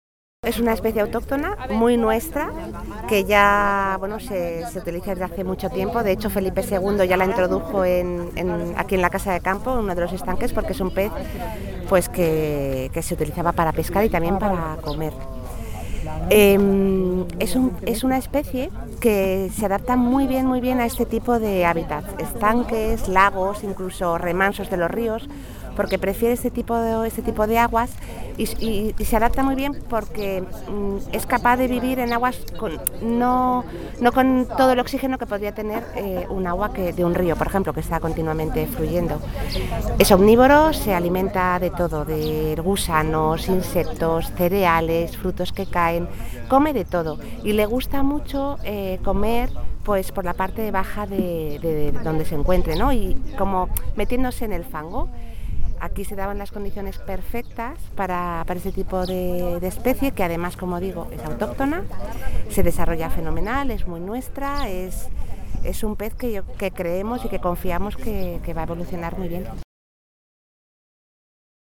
Nueva ventana:Beatriz Garcia San Gabino, directora general de Aguas y Zonas Verdes, habla sobre la liberación de tencas en el lago de Casa de Campo